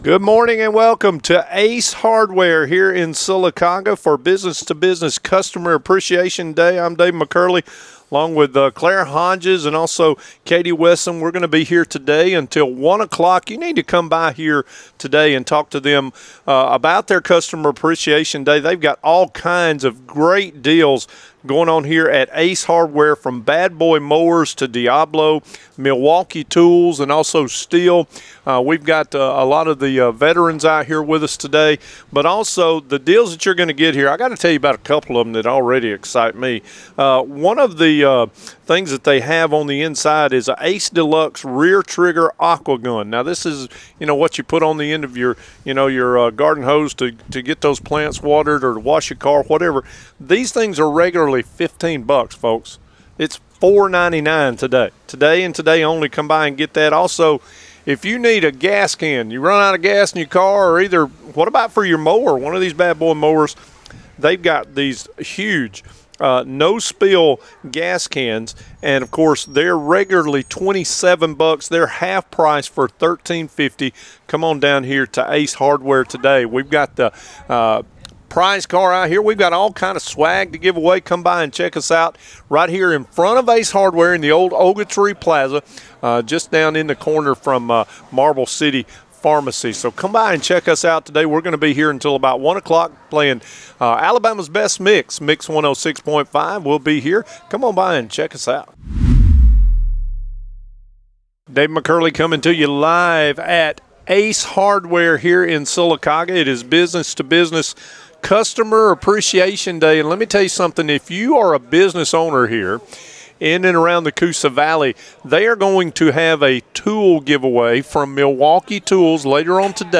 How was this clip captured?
Live from Ace Hardware in Sylacauga